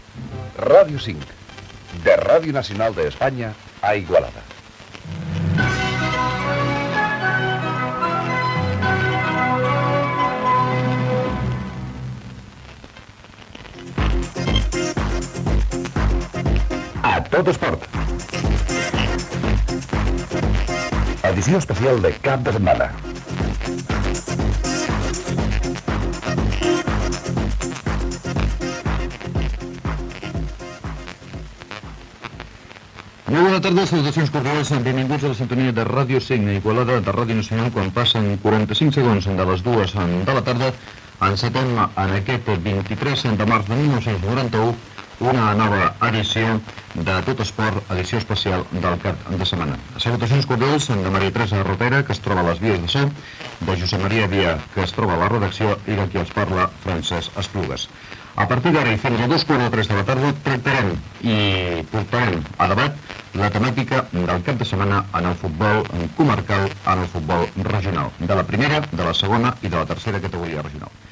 Indicatiu de l'emissora i presentació del programa.
Esportiu
FM